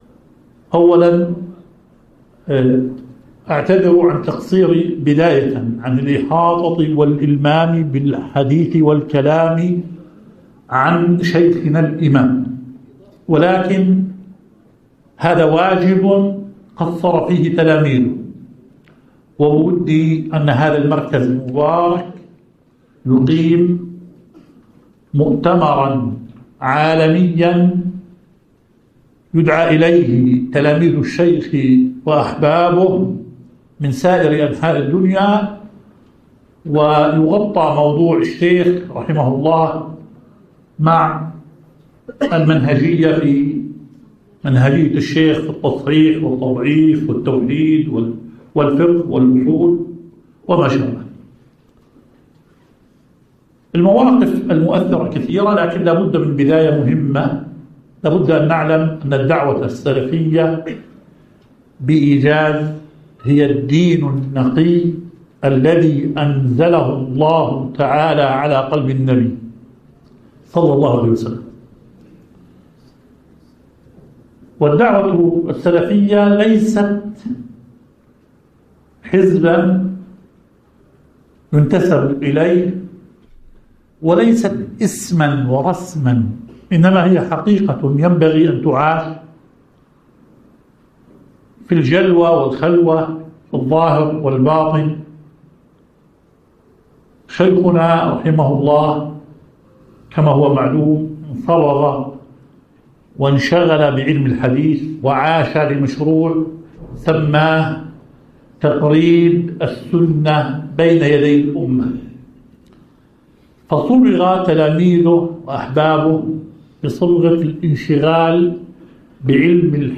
في دورة الامام الالباني التأصيل 2014